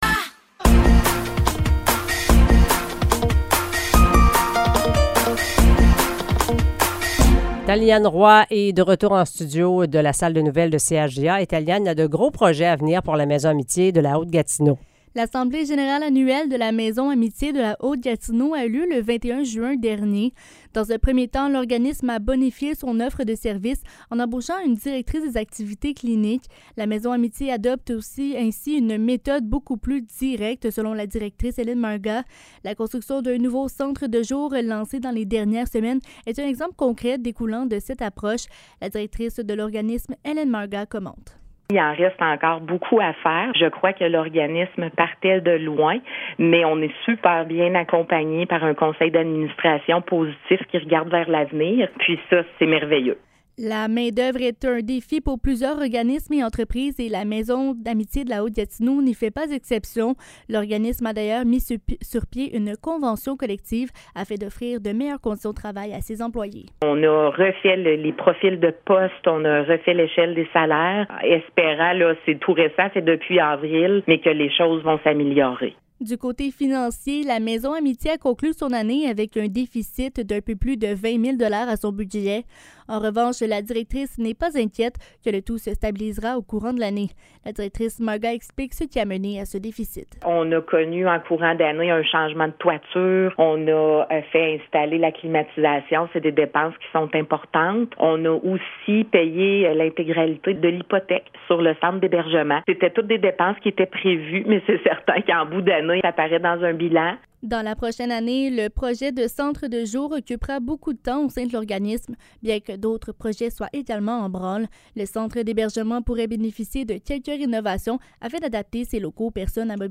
Nouvelles locales - 5 juillet 2023 - 16 h